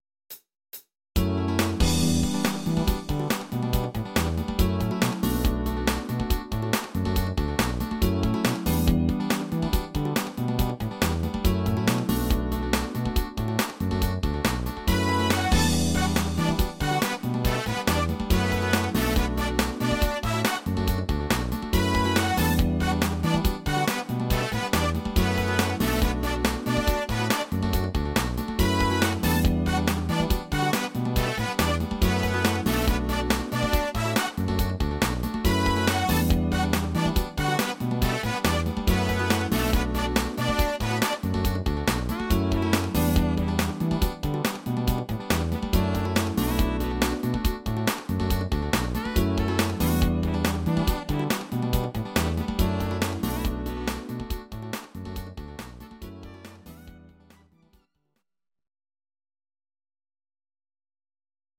Audio Recordings based on Midi-files
Pop, 1960s